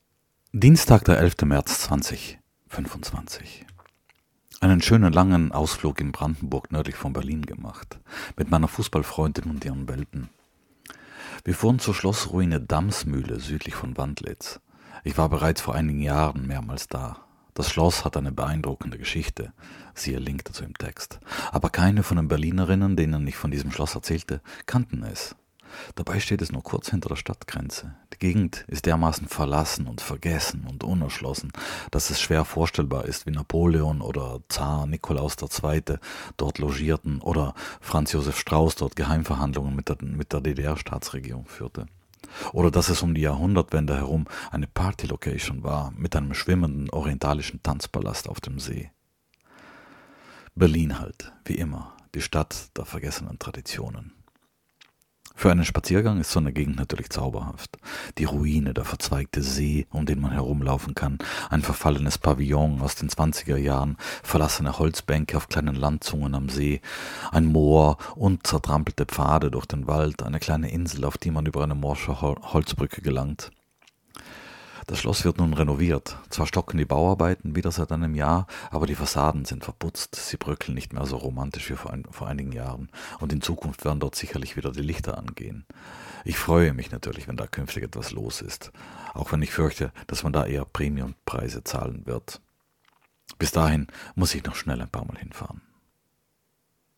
[Di, 11.3.2025 - Schlossruine Dammsmühle] - es regnet